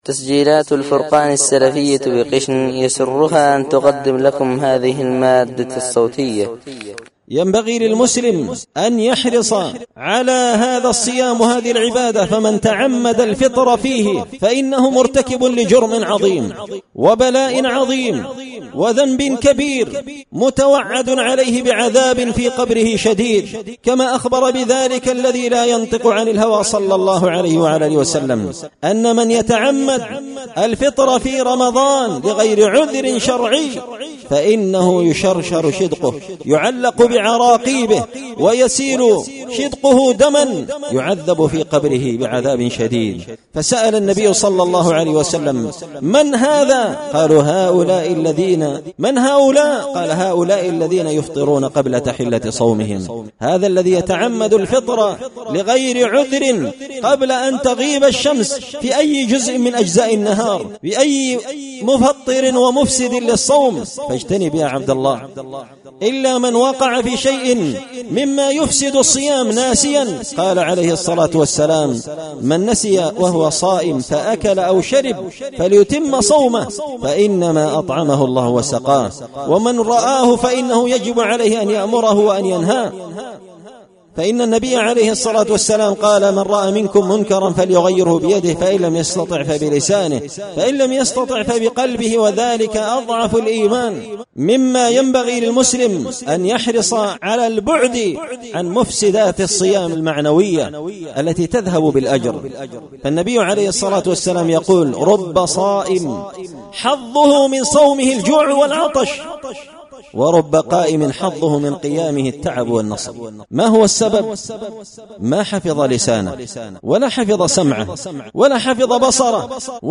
خطبة جمعة بعنوان – خصائص وآداب رمضان
دار الحديث بمسجد الفرقان ـ قشن ـ المهرة ـ اليمن